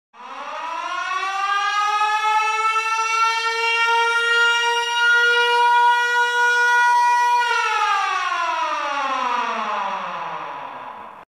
Siren Alarm Sound Button: Unblocked Meme Soundboard
Siren Alarm Sound Effects